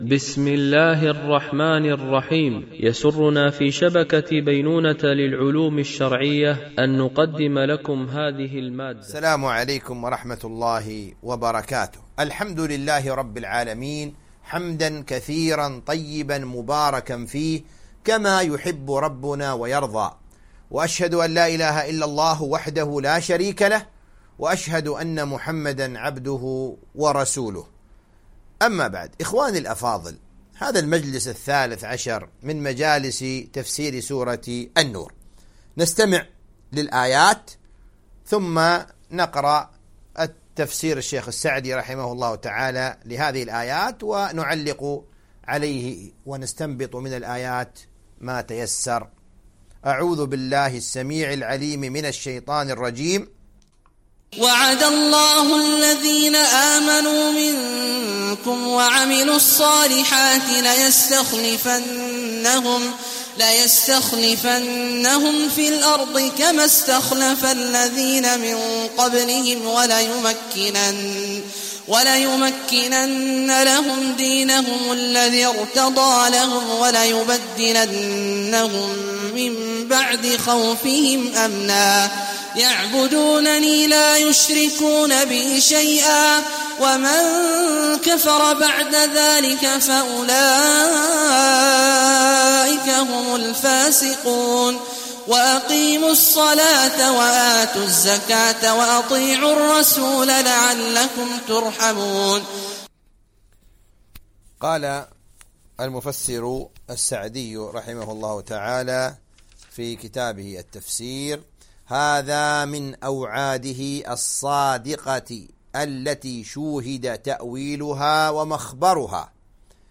سلسلة محاضرات
التنسيق: MP3 Mono 44kHz 64Kbps (VBR)